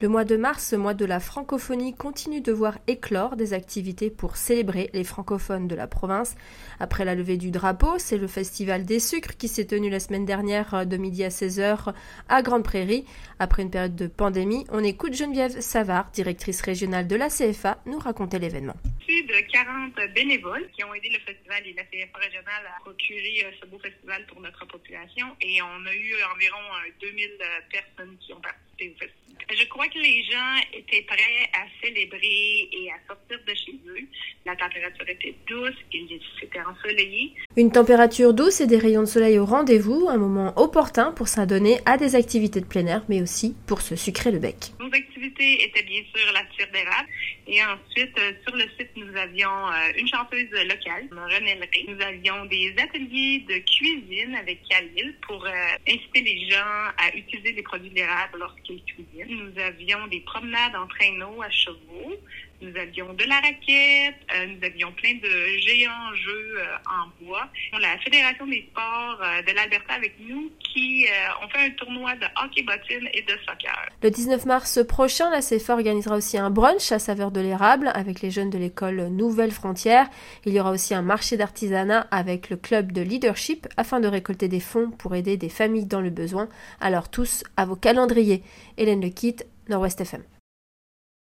Un reportage de notre journaliste